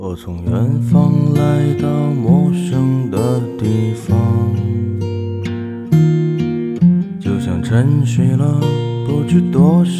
country_cn.wav